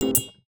Universal UI SFX / Clicks
UIClick_Smooth Tone Metallic Double Hit 02.wav